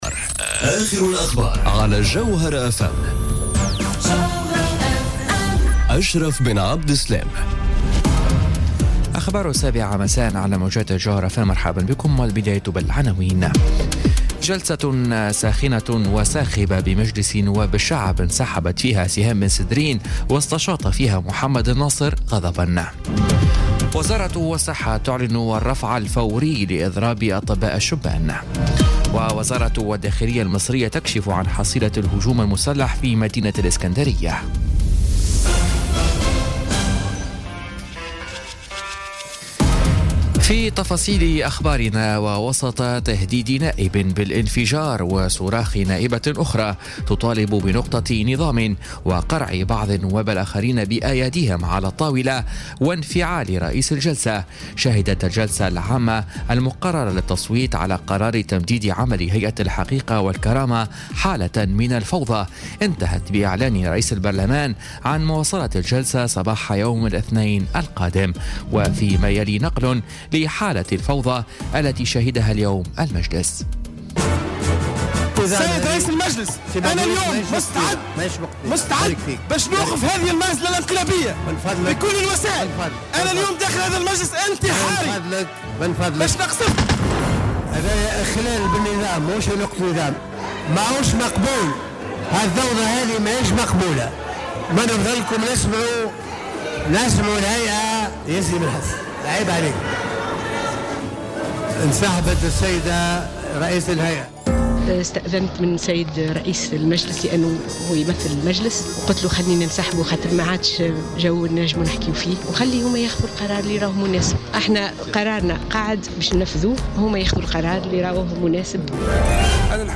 نشرة أخبار السابعة مساءً ليوم السبت 24 مارس 2018